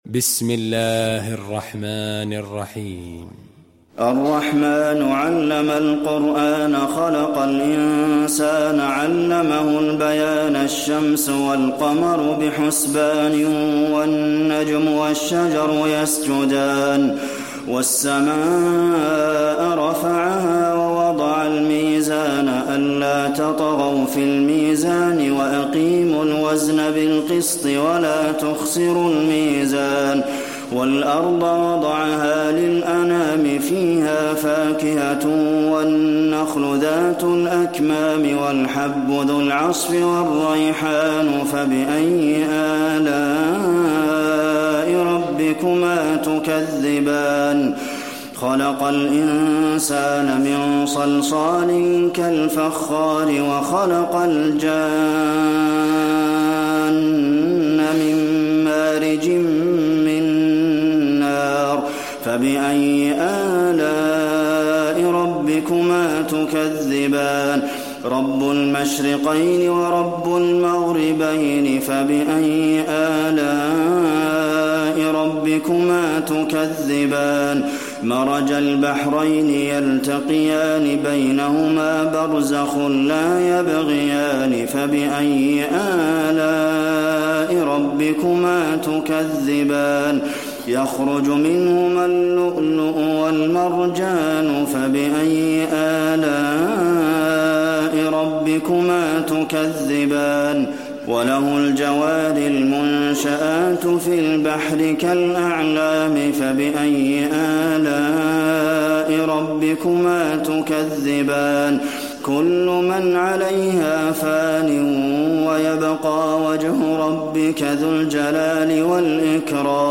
المكان: المسجد النبوي الرحمن